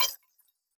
Futuristic Sounds (20).wav